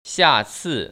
[xiàcì] 시아츠